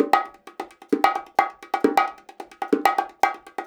130BONGO 06.wav